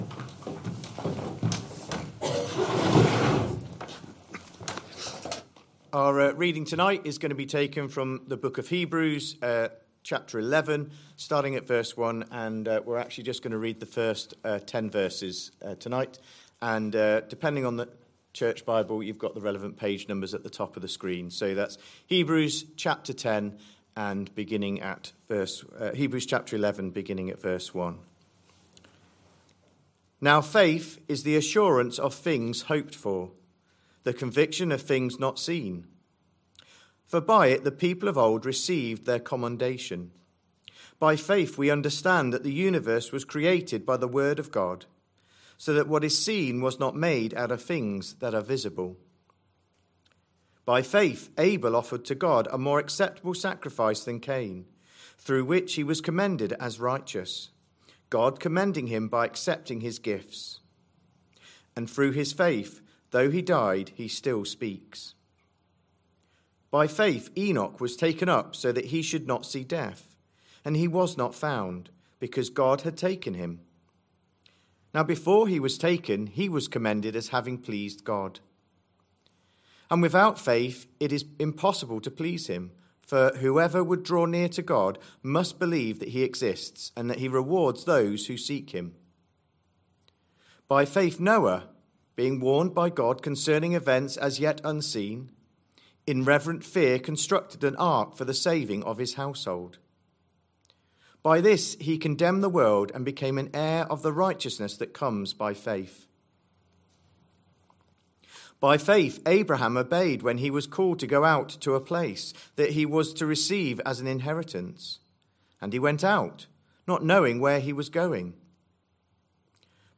Christ Church Sermon Archive
Sunday PM Service Sunday 4th January 2026 Speaker